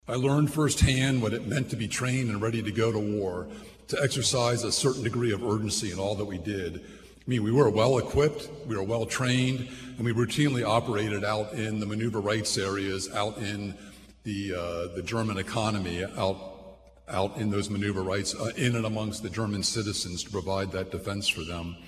The annual parade and Ceremony of Honor recognized “Veterans of the Cold War.” Retired Brigadier General and Director of the Kansas Commission of Veteran Affairs Bill Turner was the featured speaker and recalled serving in Nuremburg Germany in 1987